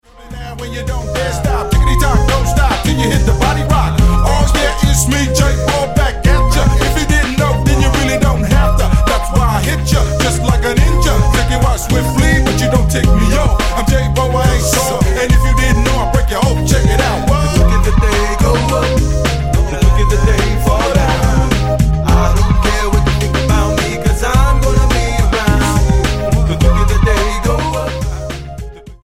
6193 Style: Hip-Hop Approach